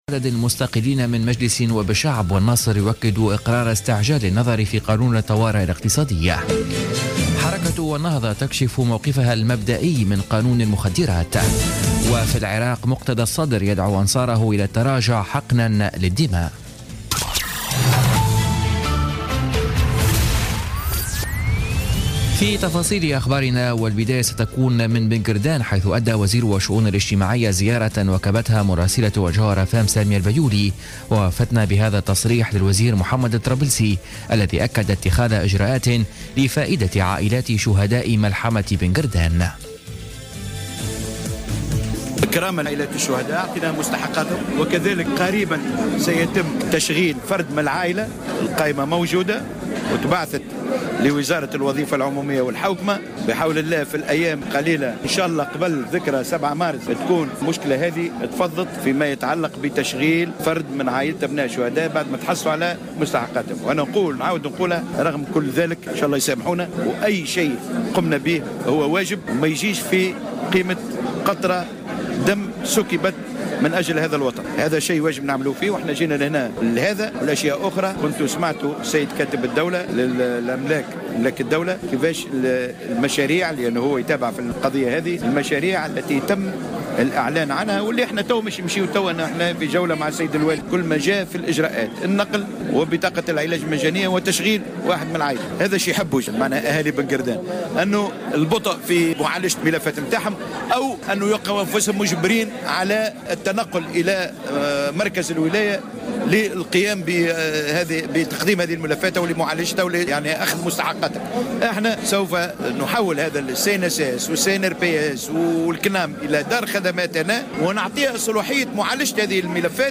نشرة أخبار السابعة مساء ليوم السبت 11 فيفري 2017